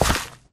Sound / Minecraft / step / gravel2